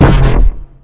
描述：激光自动快速
标签： 自动 步枪 速度快 武器 汽车 科幻 激光
声道立体声